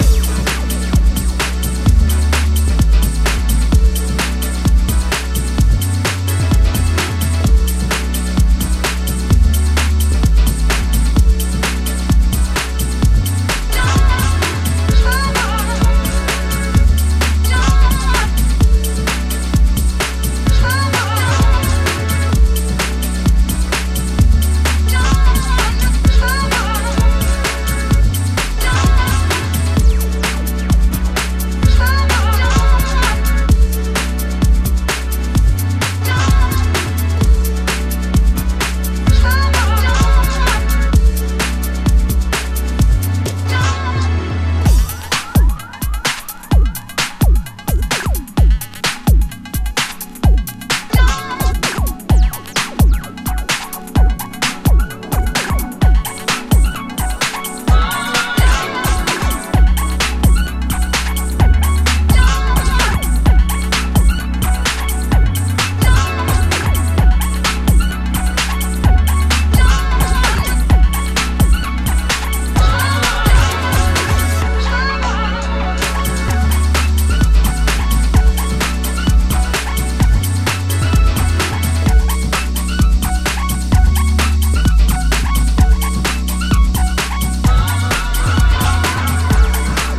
多彩なグルーヴやオーガニックでタッチでオーセンティックなディープ・ハウスの魅力を醸し、モダンなセンスで洗練された意欲作。